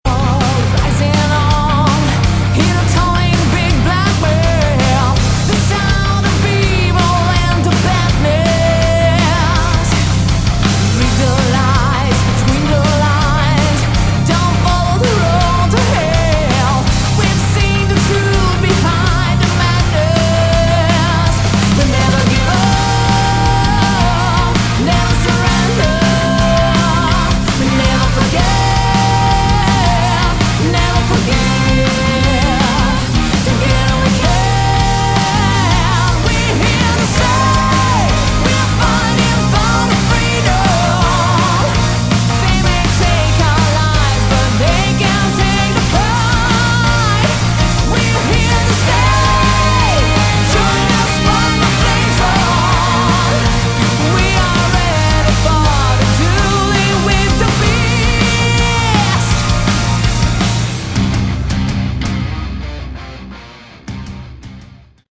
Category: Hard Rock
drums